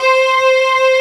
14 STRG C4-R.wav